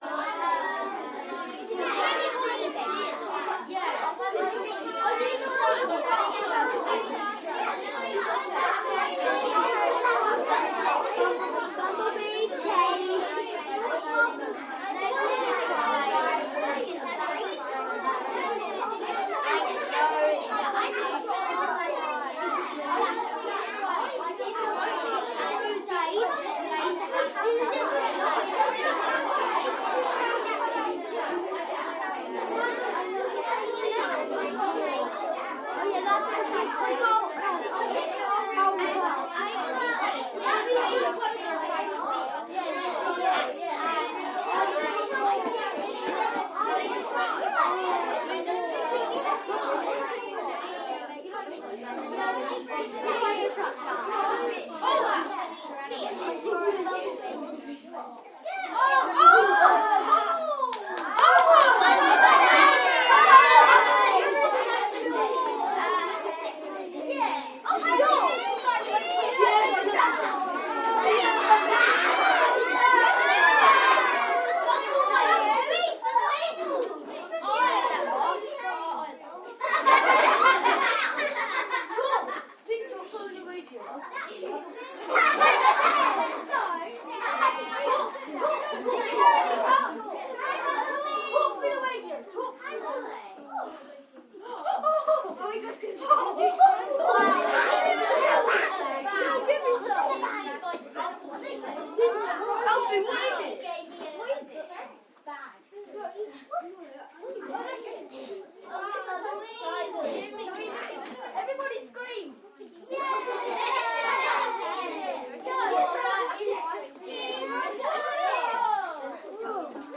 Звуки школы
Шумный класс английскоговорящих детей 6–9 лет: смех, крики и хлопки